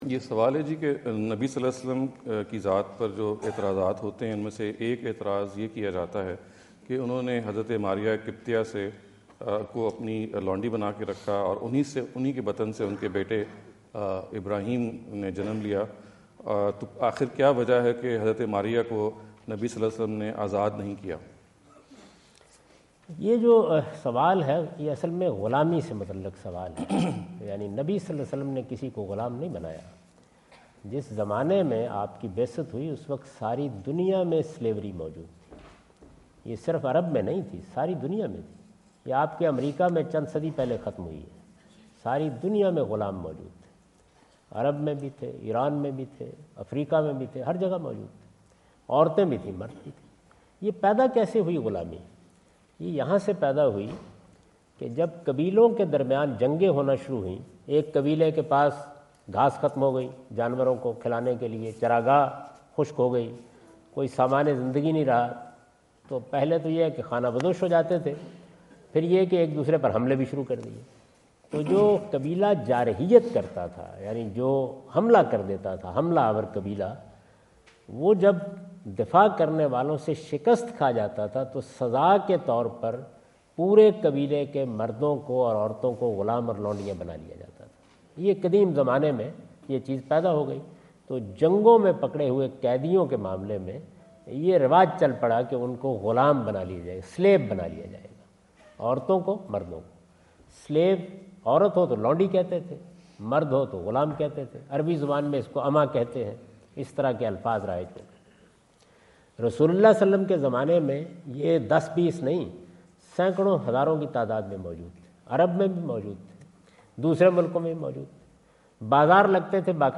Javed Ahmad Ghamidi answer the question about "ٰWhy Prophet Muhammad (PBUH) Could not Free Maria Qibtiyah?" asked at The University of Houston, Houston Texas on November 05,2017.